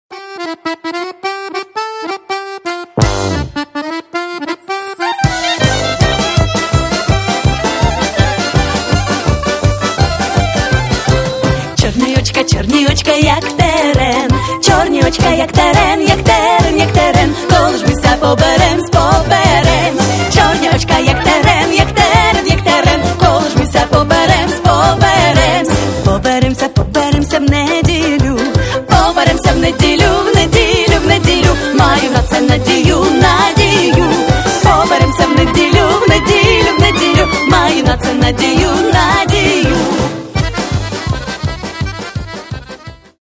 Каталог -> Народна -> Сучасні обробки